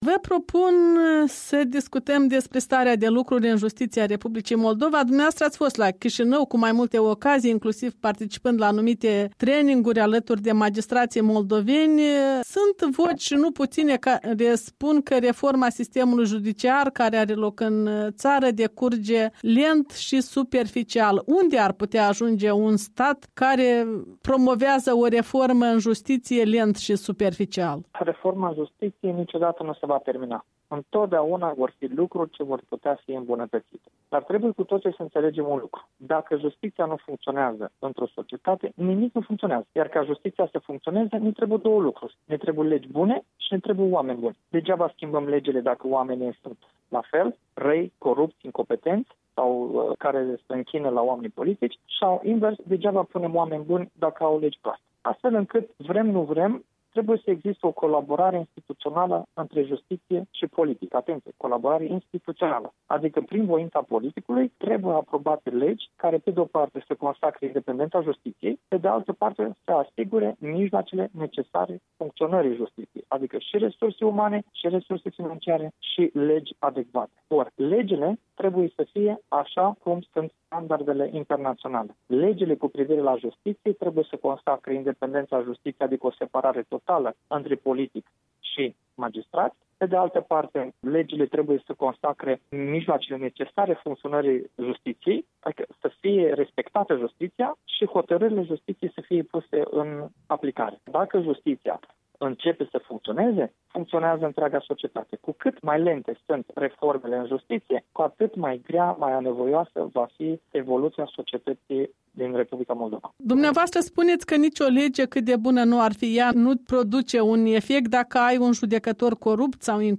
Un interviu cu judecătorul român Cristi Danileţ